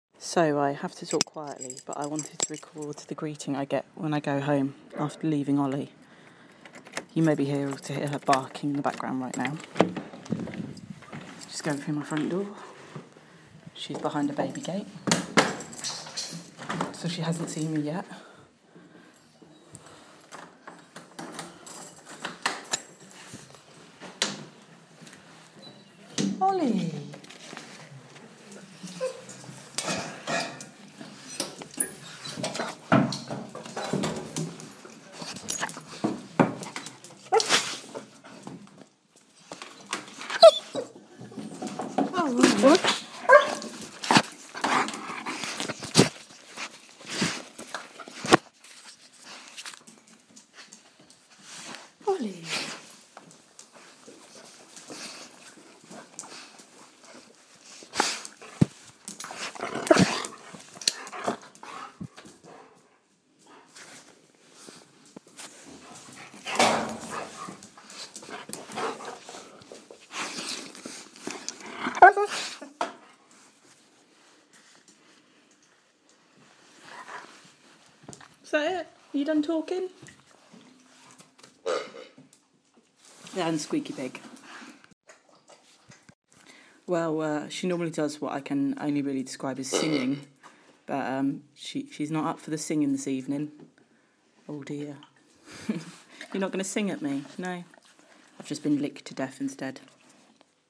Ollie sings when I walk through the door
The one time I record her she doesn't sing!